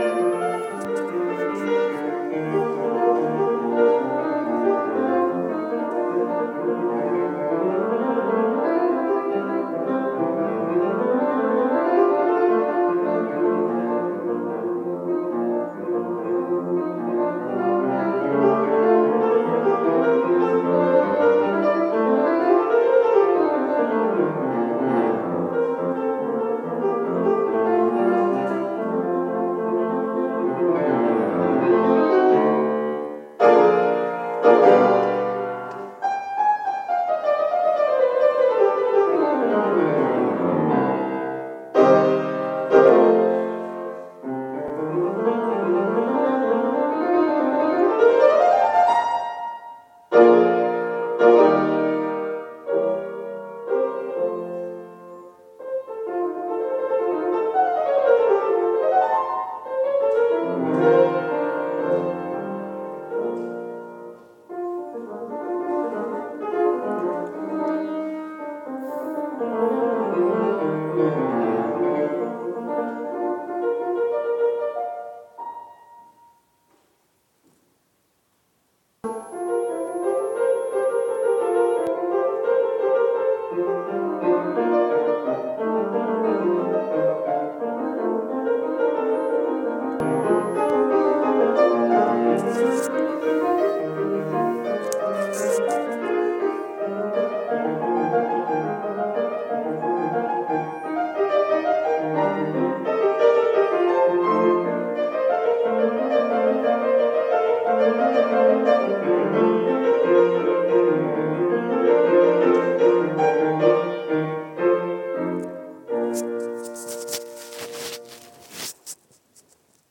Klavierabend 2